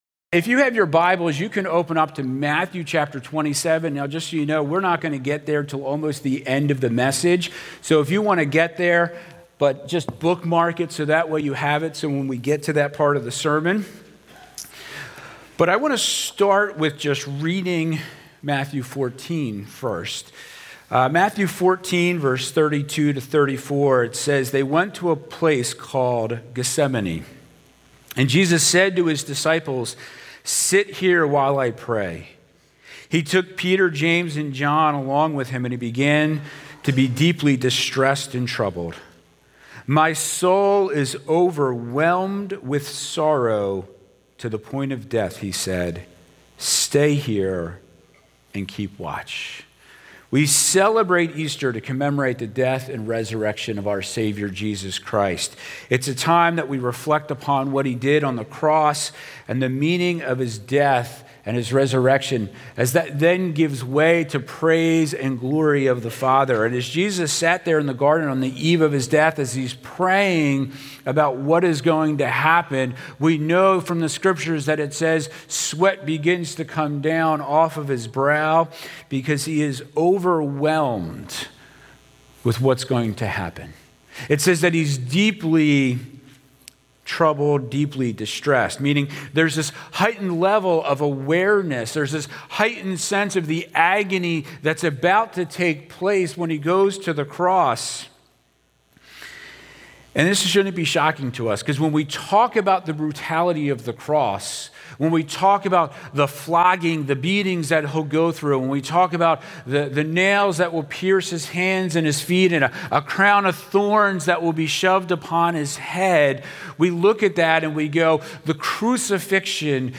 Sermons from Penn Valley Church: Telford, PA